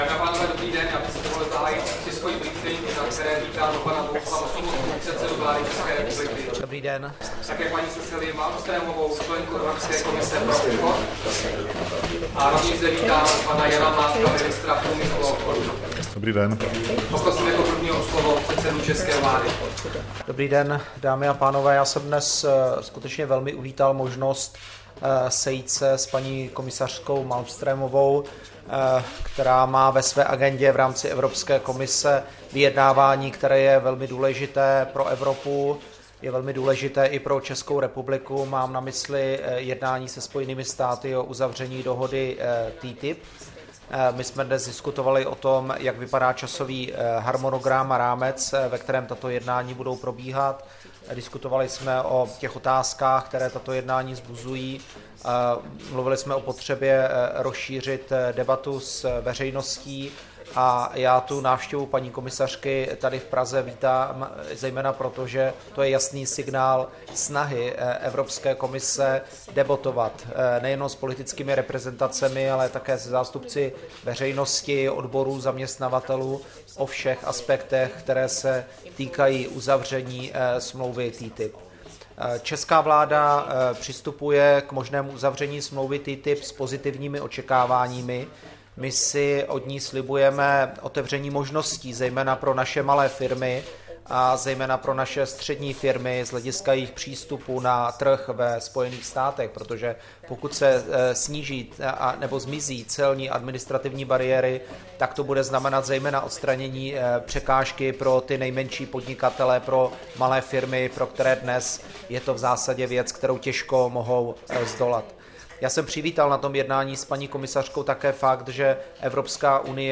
Tisková konference po setkání premiéra Sobotky se členkou Evropské komise pro obchodní politiku Malmströmovou, 5. června 2015